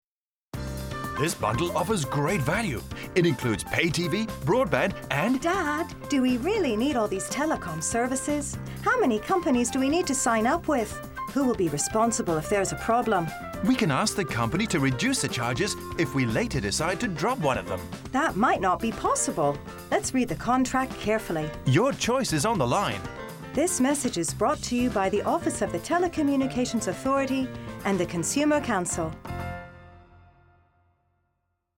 Radio Announcements